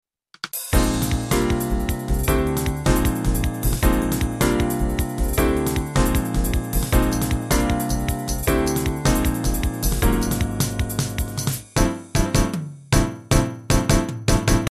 PCIサウンドカード　SB Live! MIDI Synth（ハード）[MP3ファイル]
音色は好きなのだがドラム･パーカッションの音が質素に聴こえる。